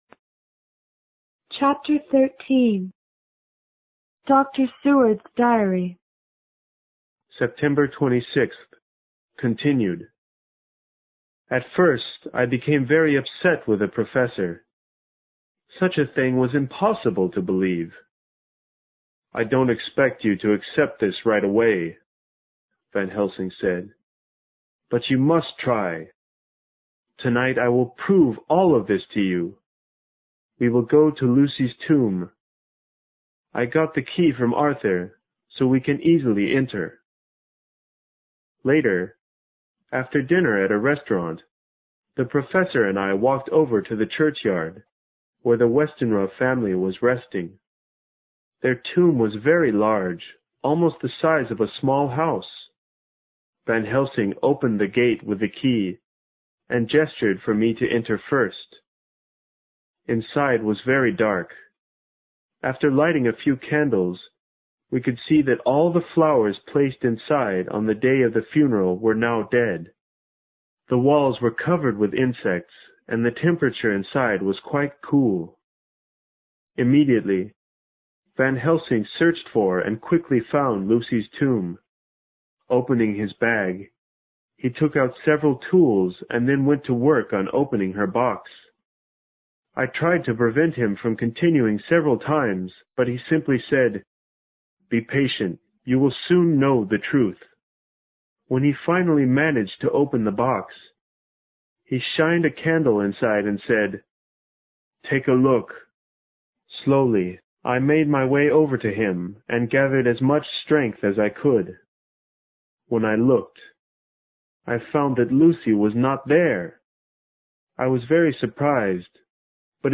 有声名著之吸血鬼 Chapter13 听力文件下载—在线英语听力室